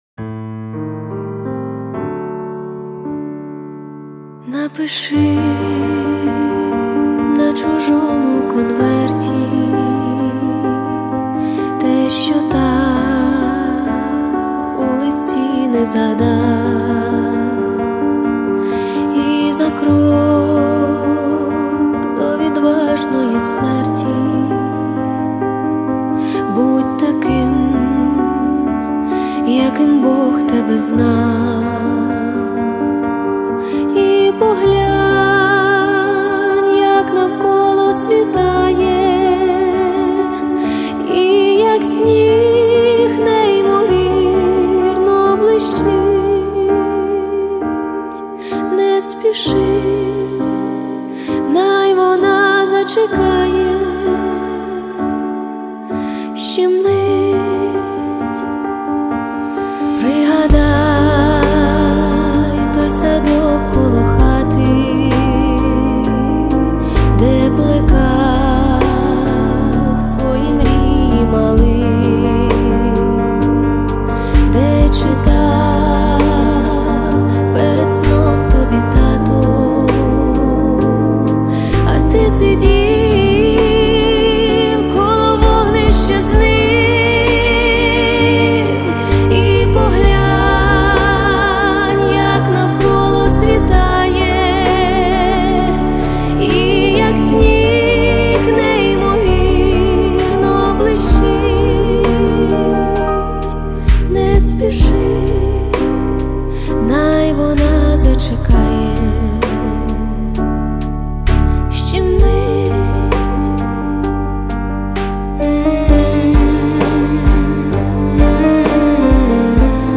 • Качество: 320 kbps, Stereo
кавер